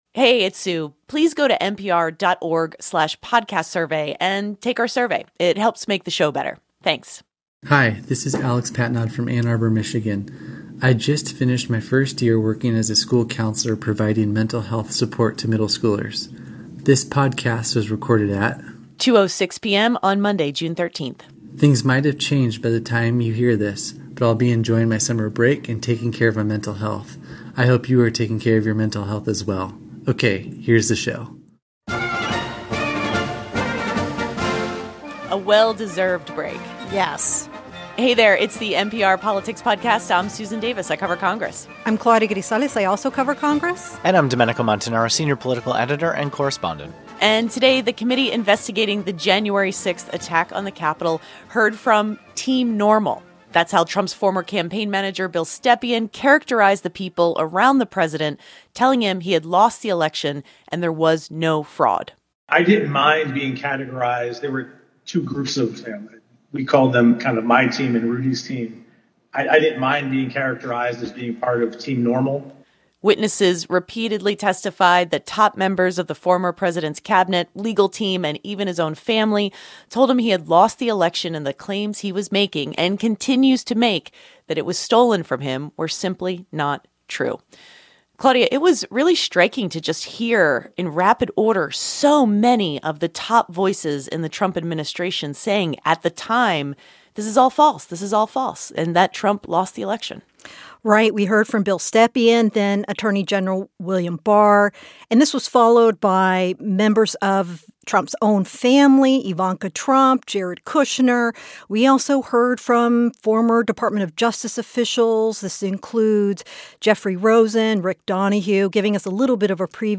The second hearing into the Jan. 6 insurrection featured a slew of clips from top Trump aides from the campaign and administration testifying that the former president was repeatedly told that voter fraud claims were not true — but he continued to double-down, both publicly and privately.